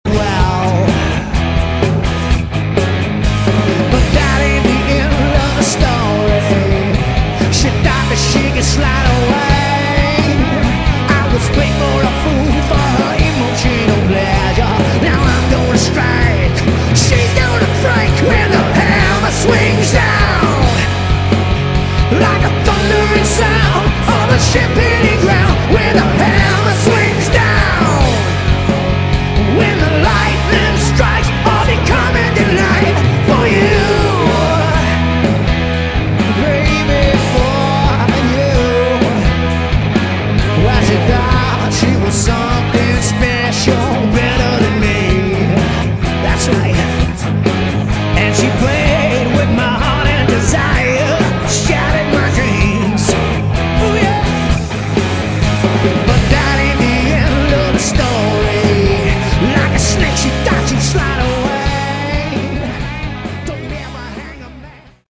Category: Hard Rock
Lead Vocals, Acoustic Guitar
Backing Vocals
Guitar
Bass
Drums